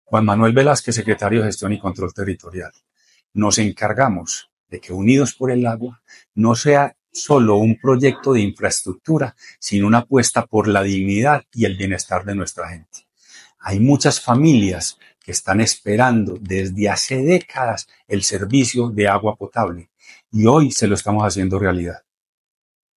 Palabras de Juan Manuel Velásquez Correa, secretario de Gestión y Control Territorial